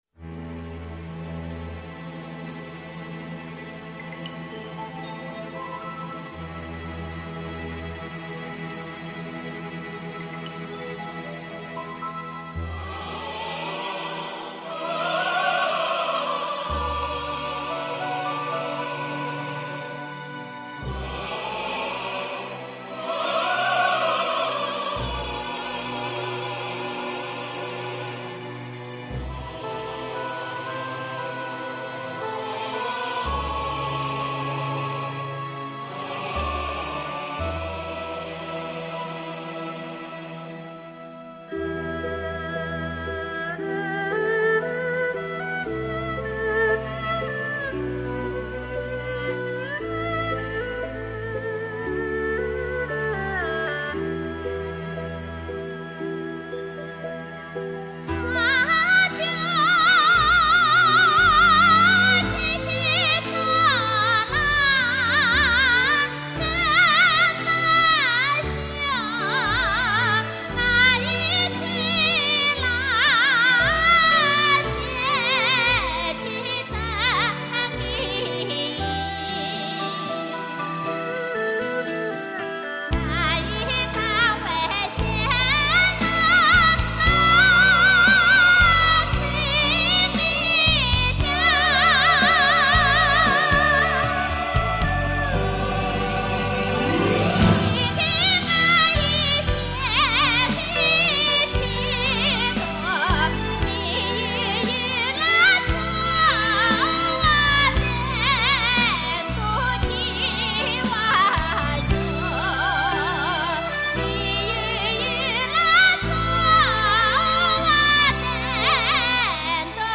演唱充满深情，曲调优美动听、感人致深，当时曾深深打动了无数观众，受到人们的喜爱。